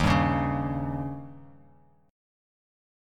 Eb7 Chord
Listen to Eb7 strummed